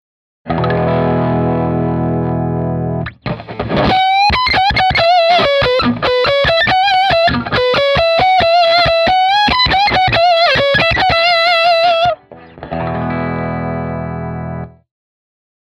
I wanted to emulate the fattest lead sound possible and the smoothest ever.
I cleaned everything up with later designs but left one position on the frequency selector ( the highest ) for the real early fuzz effects in case you want to use it.